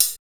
5214R HH.wav